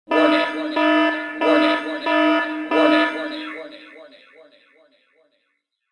selfdestructstart.ogg